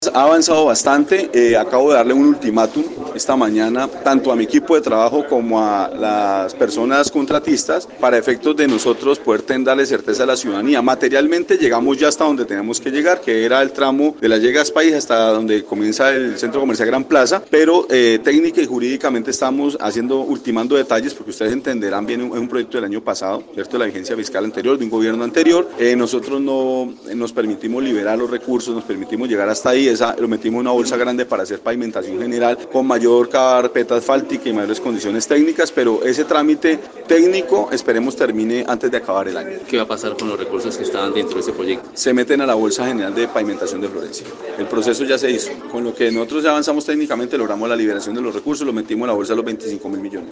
Así lo dio a conocer el alcalde Monsalve Ascanio.
ALCALDE_MONSALVE_ASCANIO_PUENTES_-_copia.mp3